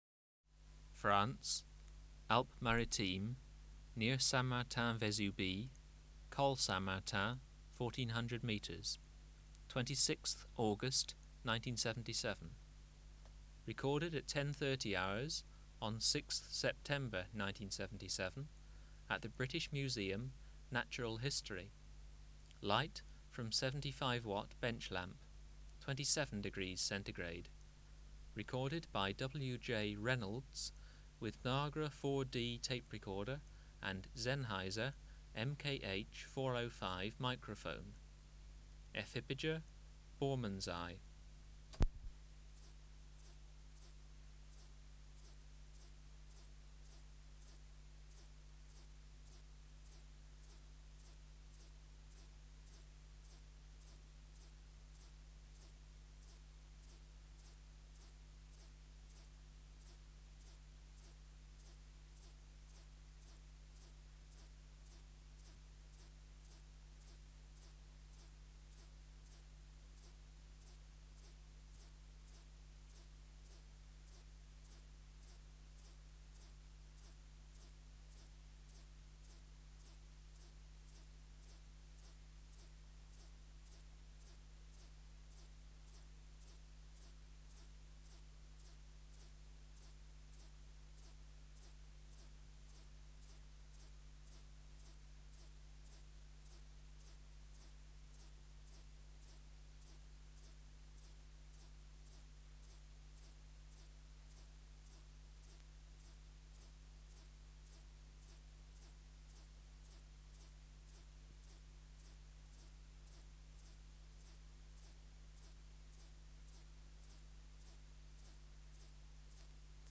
Recording Location: BMNH Acoustic Laboratory
Reference Signal: 1 kHz for 10s
Substrate/Cage: Recording cage
Microphone & Power Supply: Sennheiser MKH 405 Distance from Subject (cm): 30
Recorder: Kudelski Nagra IV D (-17 dB at 50Hz)